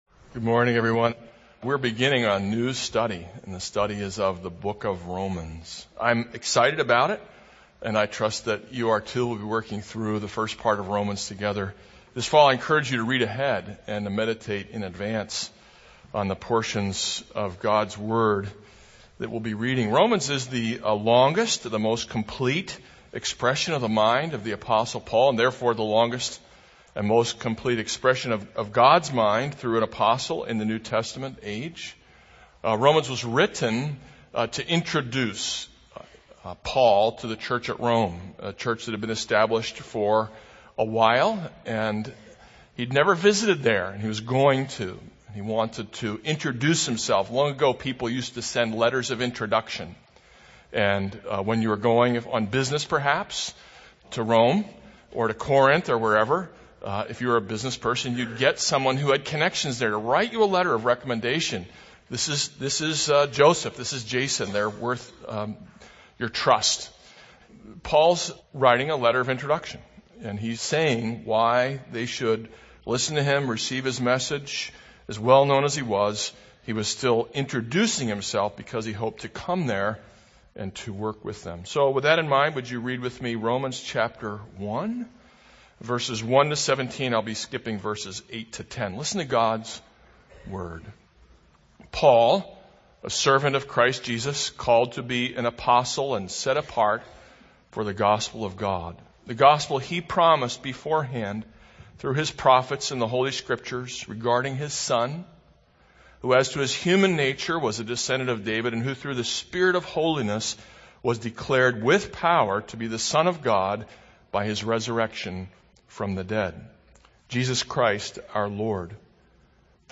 This is a sermon on Romans 1:1-17.